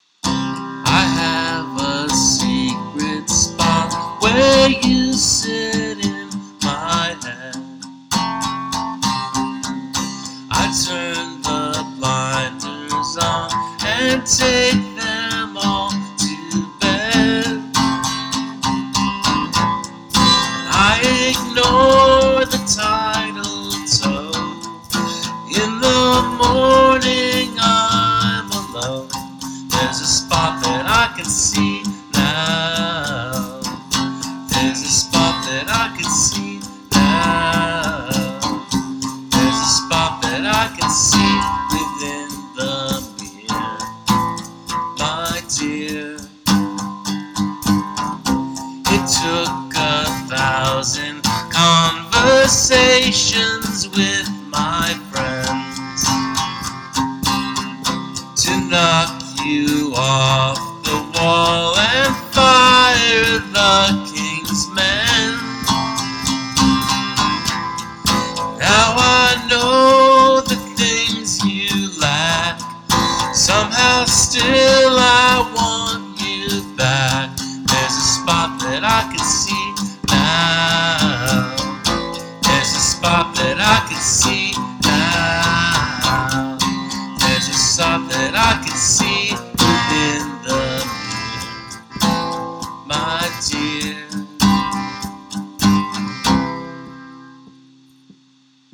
Hand percussion
Lyrics/vocals more heartfelt.
Definitely has that early 2000's album closer feel.